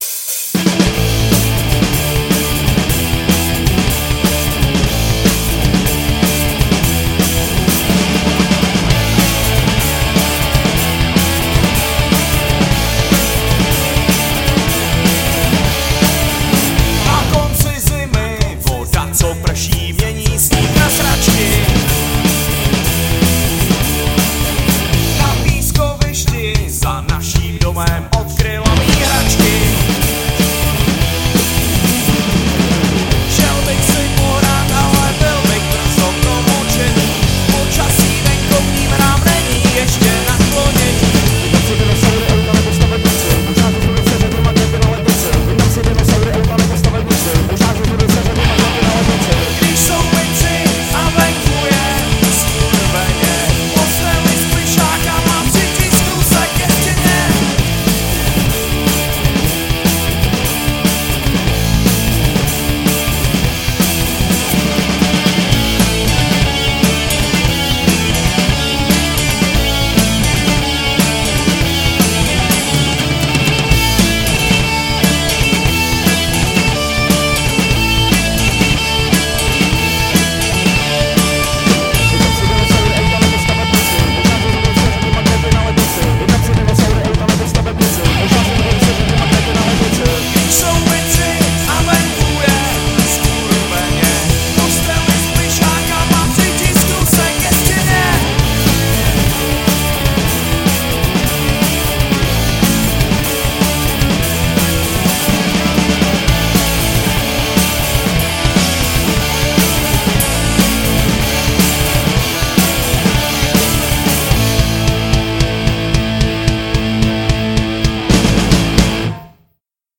Žánr: Punk